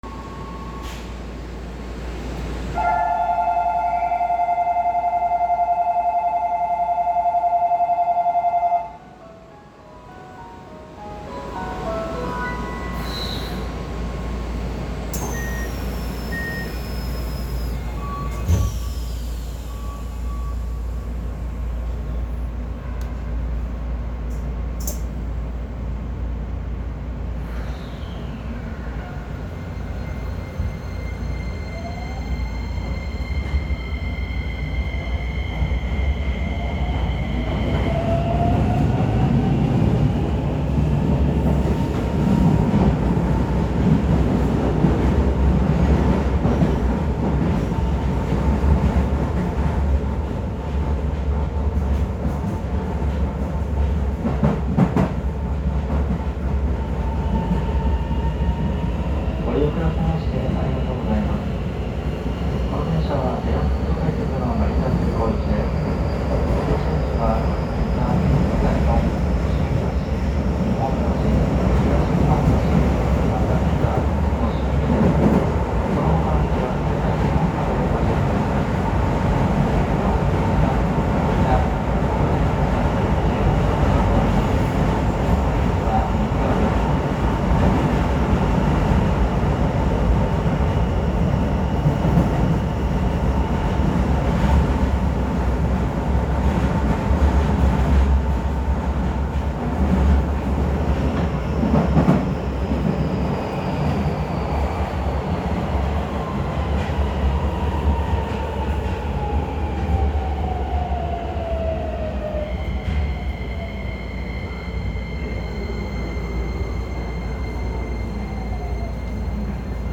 ・3100形走行音
世にも珍しい東洋SiCとなります。…が、東洋IGBTと音の聞こえ方はほぼ変わらず、新鮮味は全くありません。